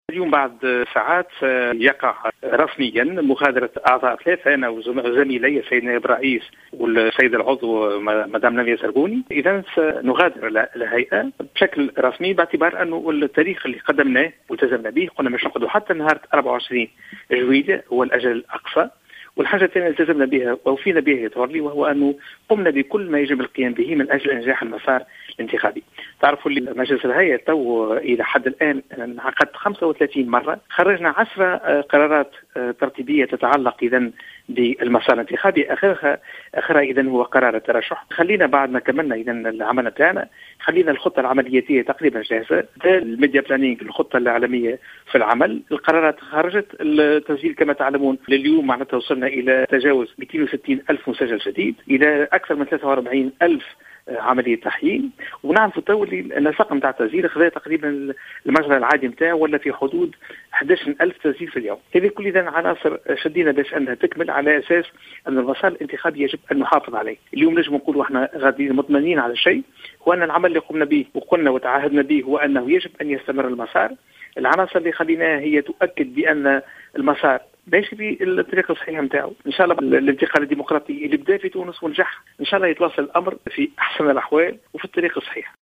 قال رئيس الهيئة العليا المستقلة للانتخابات في تصريح للجوهرة "اف ام" أنه سيغادر الهيئة رسميا بداية من يوم غد الإثنين 24 جويلية 2017 رفقة رفقة نائبين اخرين بعد أن كانوا قد أعلنوا في شهر ماي الفارط عن تقديم استقالتهم.